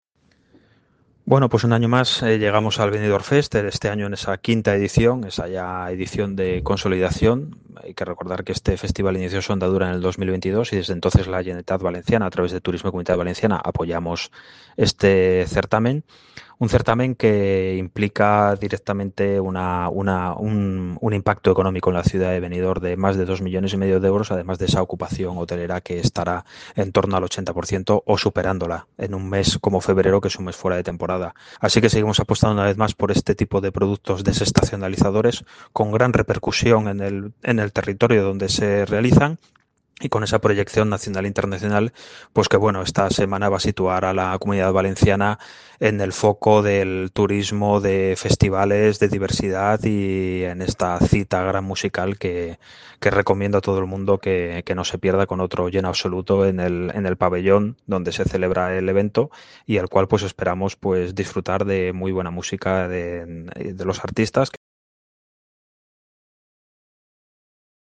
Declaraciones del Director general de Turismo, Israel Martínez 👇